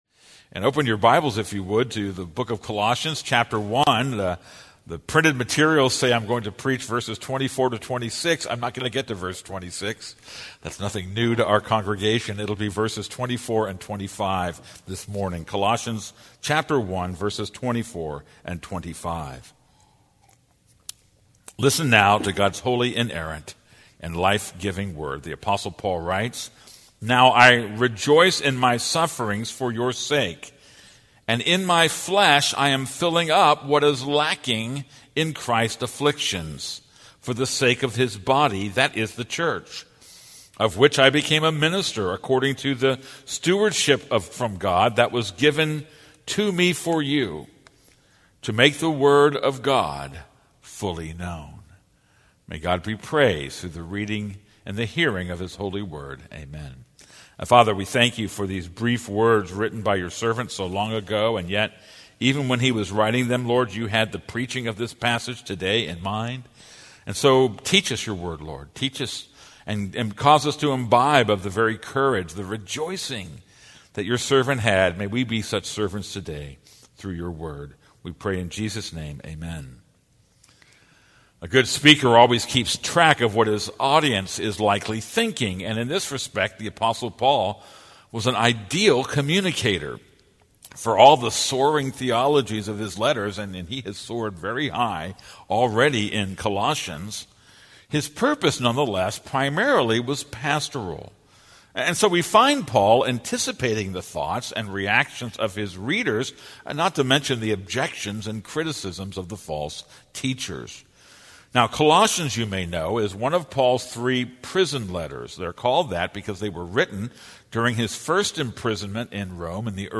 This is a sermon on Colossians 1:24-25.